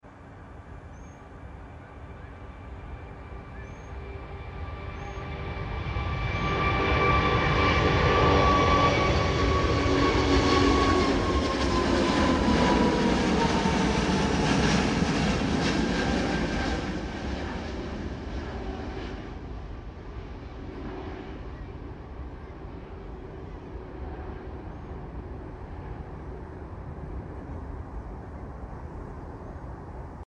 Airbus A380-841 British Airways -